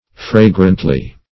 fragrantly - definition of fragrantly - synonyms, pronunciation, spelling from Free Dictionary
Fra"grant*ly, adv.